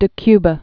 (də kybə, dĕ kvä)